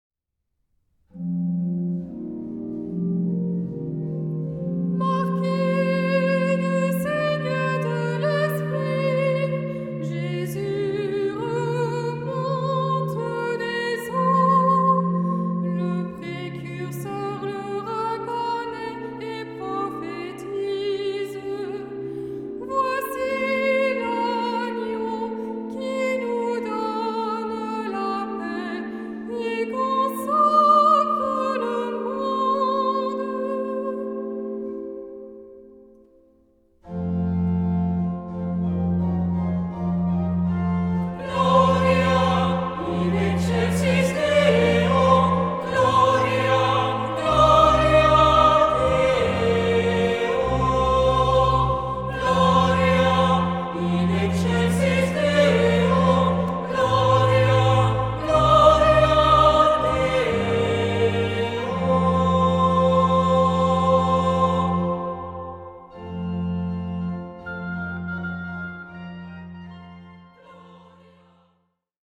Genre-Style-Form: troparium ; Psalmody ; Sacred
Mood of the piece: collected
Type of Choir: SAH OR SATB  (4 mixed voices )
Instruments: Organ (1) ; Melody instrument (optional)
Tonality: F major